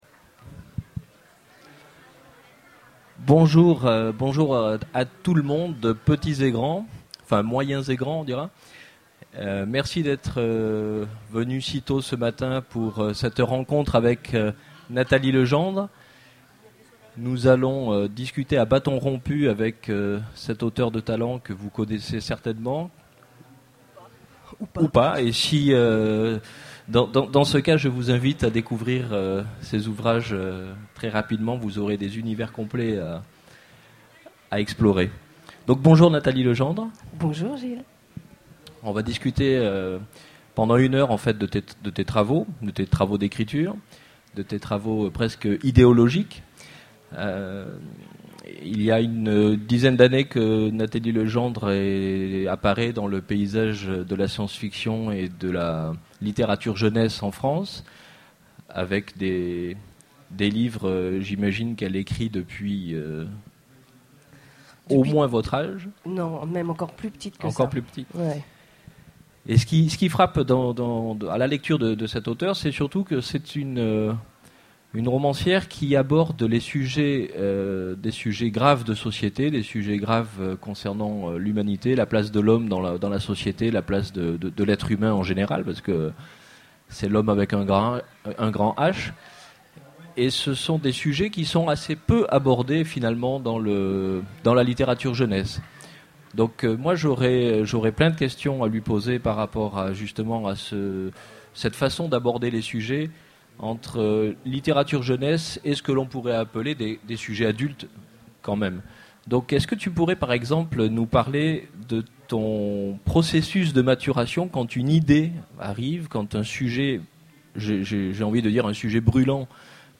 Conférence
Mots-clés Rencontre avec un auteur Conférence Partager cet article